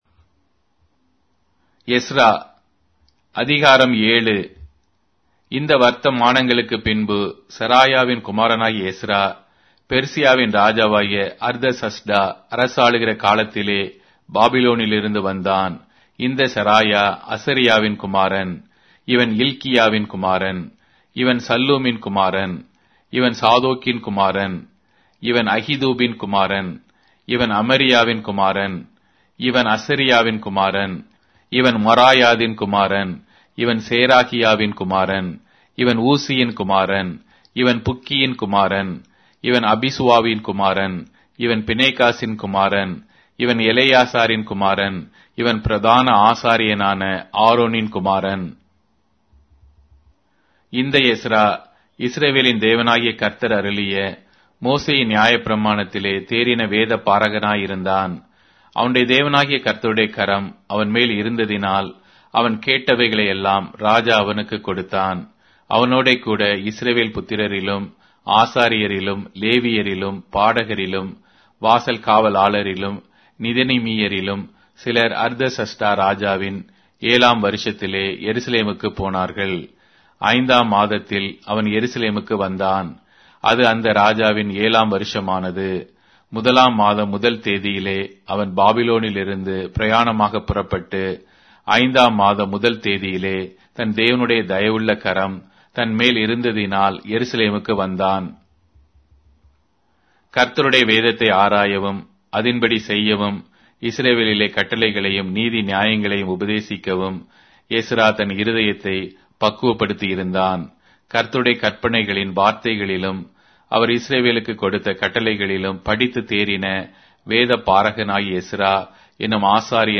Tamil Audio Bible - Ezra 6 in Guv bible version